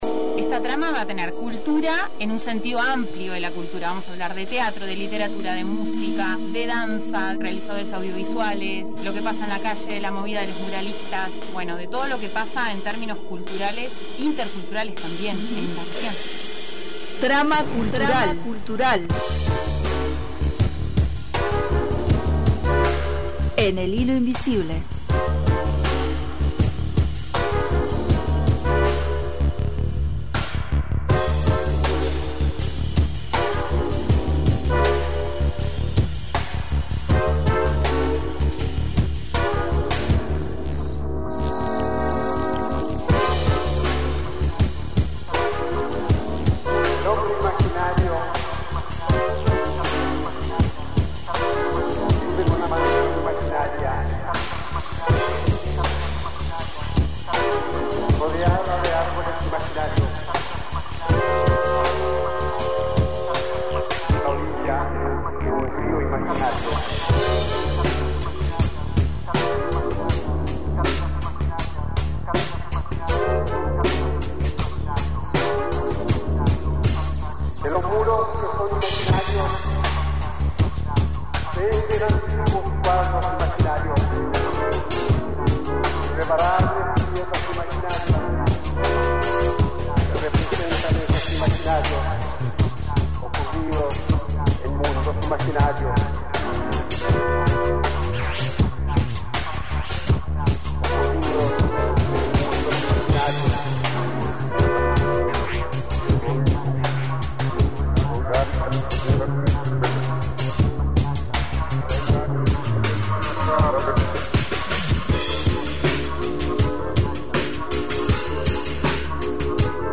En este micro de “Trama Cultural”, la conversación se adentra en el cruce entre teatro, comunidad y reflexión vital a partir de la obra “Memento Mori” y la experiencia del taller municipal de teatro. Se destaca al teatro como un espacio de juego, encuentro y descubrimiento, capaz de ofrecer nuevas miradas sobre la realidad y abrir temas profundos, como la muerte, desde la sensibilidad y sin solemnidad.